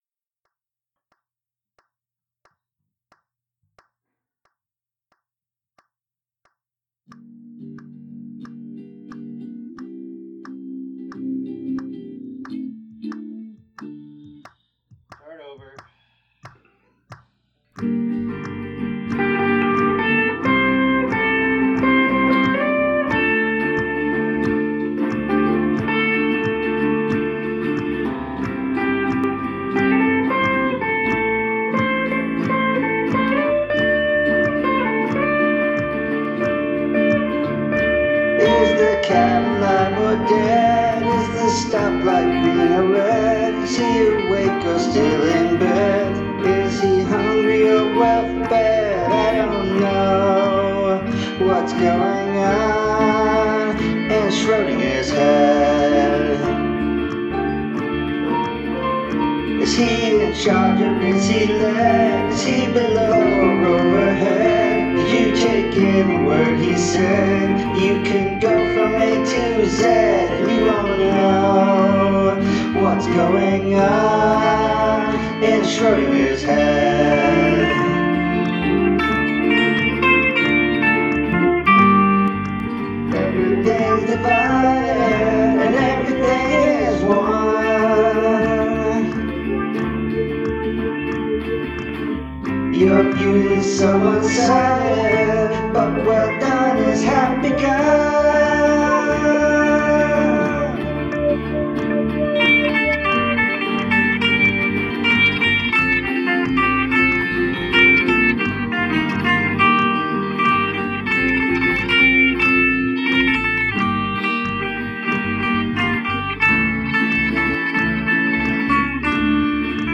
After all, I had to do all the parts and the mixing myself.
I can pull off most all of the other instruments on my own – more or less – but it would have been nice to have had real percussion here.
schrodingers-head-stereo.mp3